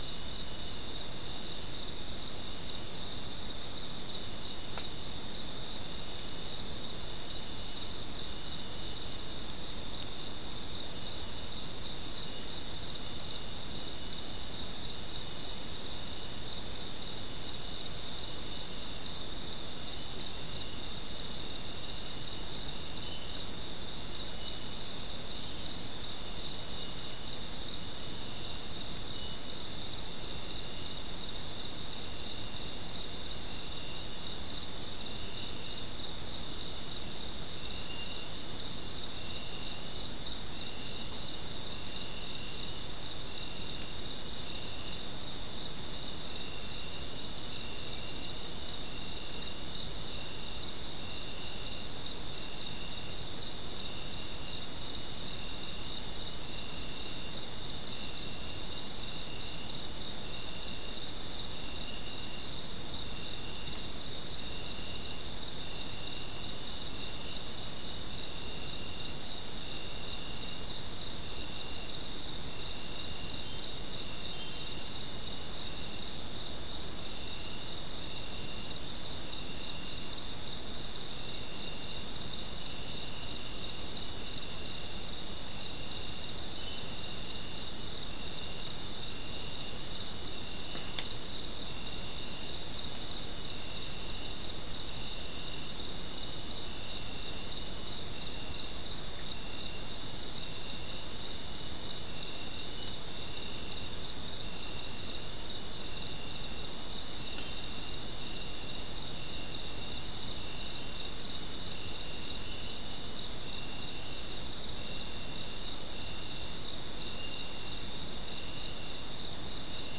Non-specimen recording: Soundscape Recording Location: South America: Guyana: Sandstone: 2
Recorder: SM3